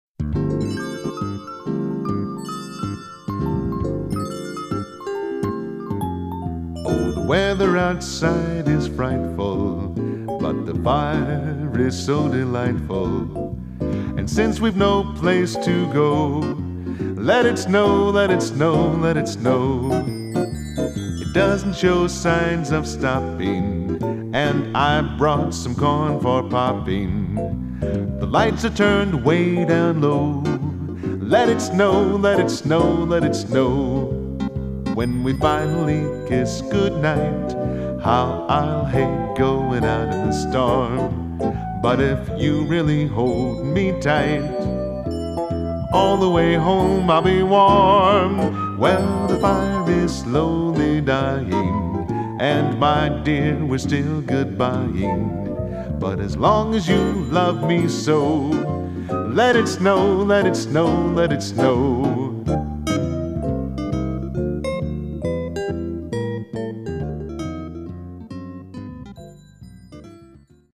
piano/keyboard/vocals